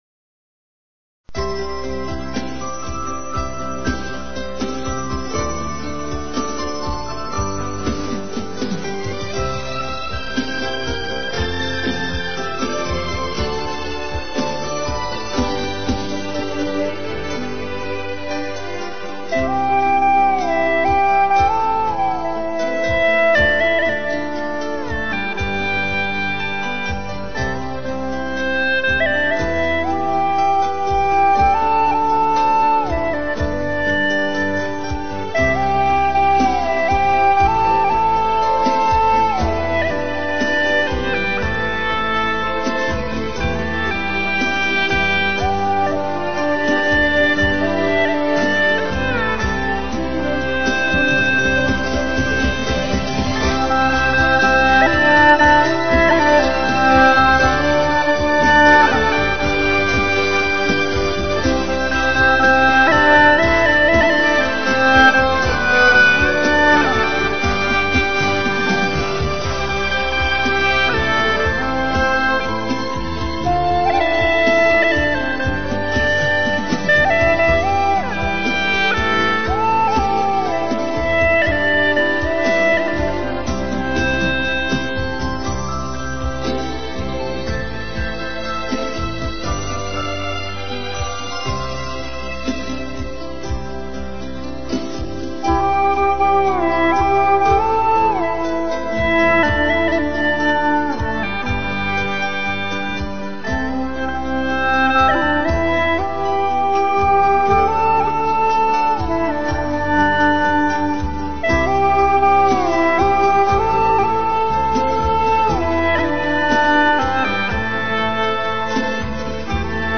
调式 : C 曲类 : 红歌